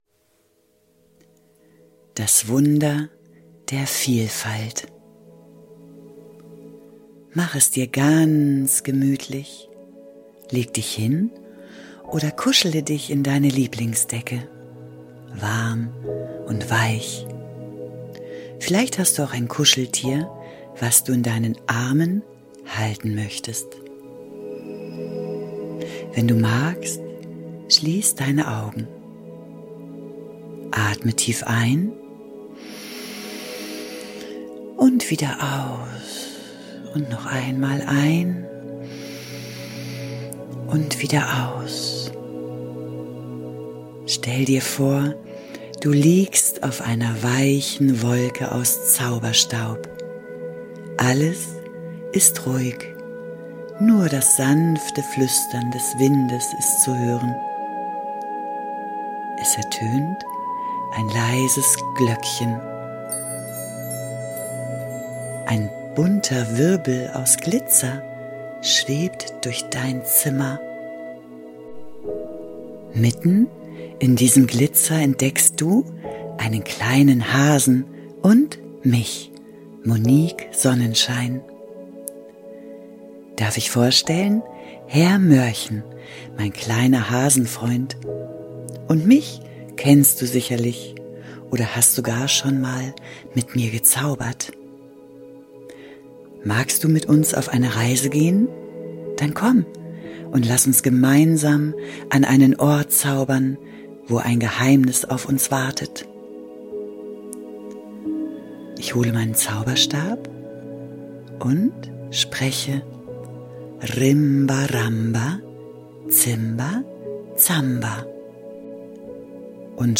Tauche ein in eine neue Reise mit 432Hz